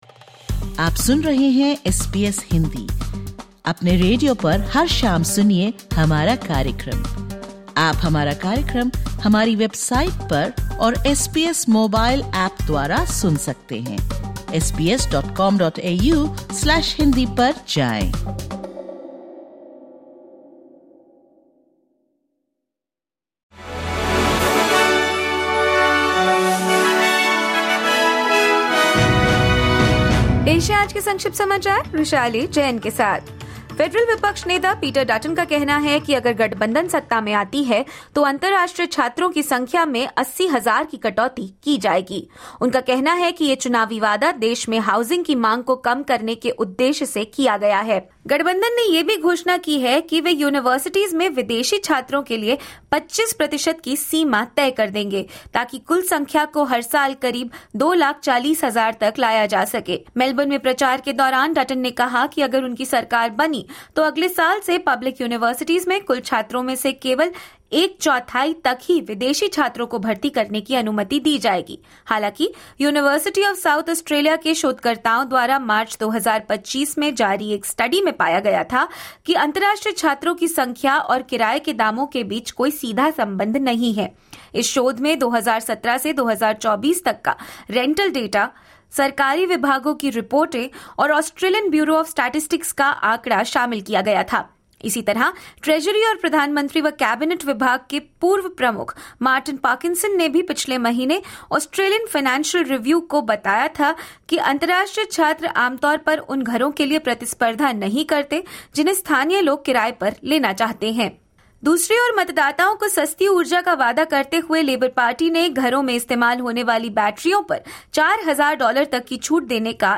Listen to the top News of 06/04/2025 from Australia in Hindi.